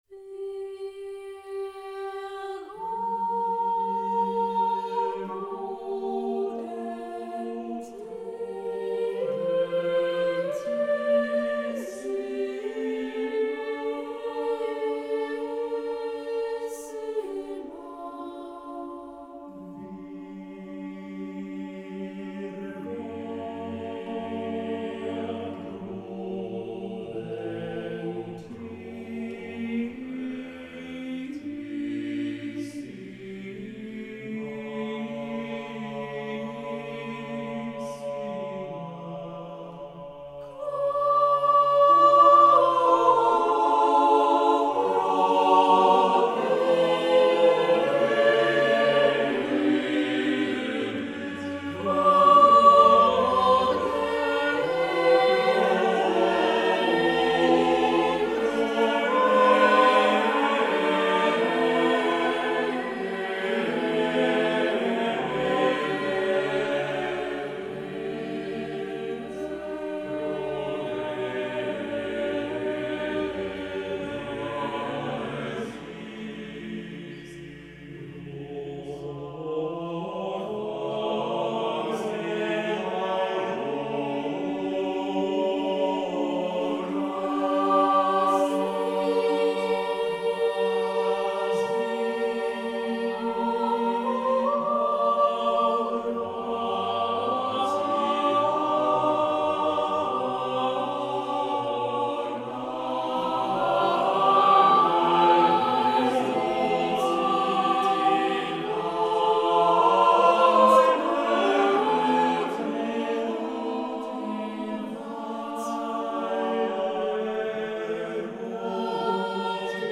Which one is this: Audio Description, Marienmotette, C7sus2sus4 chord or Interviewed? Marienmotette